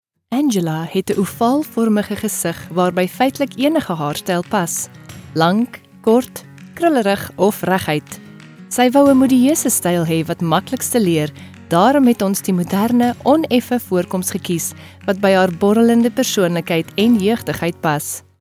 Narration
Behringer C1 Condenser microphone
Sound-proofed room
HighMezzo-Soprano